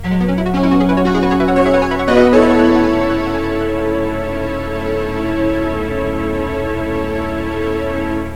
Senyal desconnexió/connexió
Banda FM